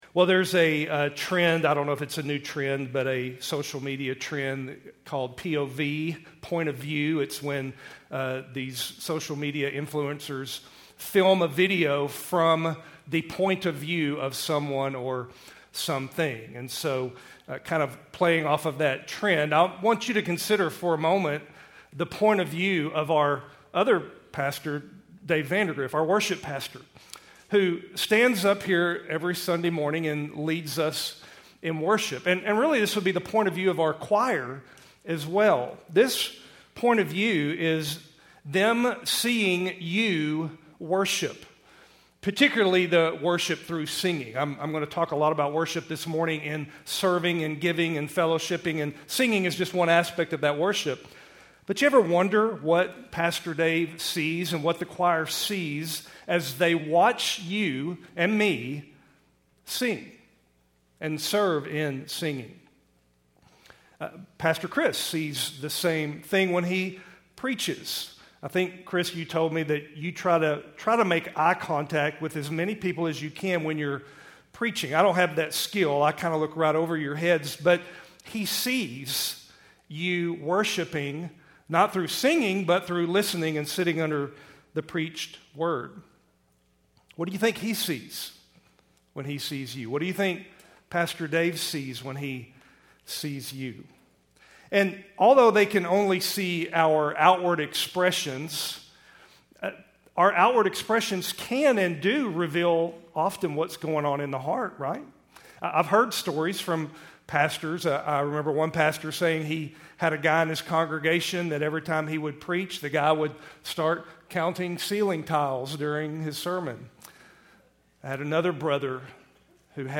Keltys Worship Service, November 24, 2024